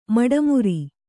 ♪ maḍamuri